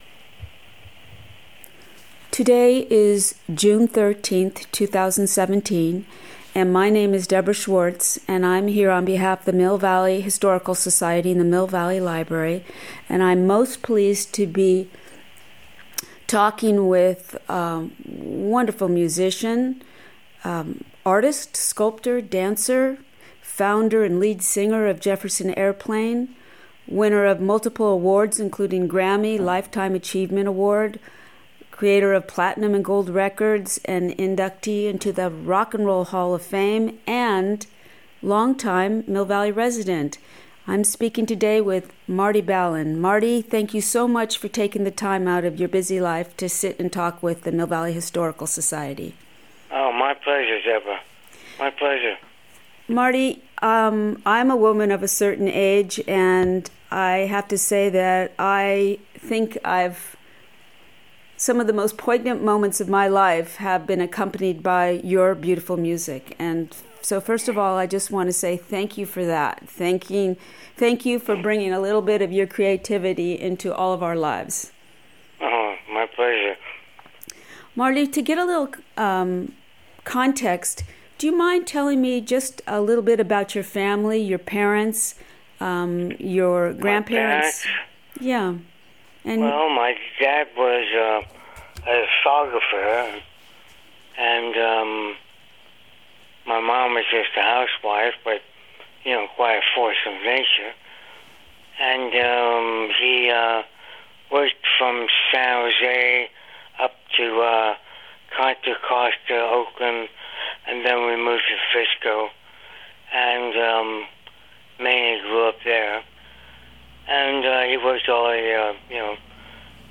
Oral history - Music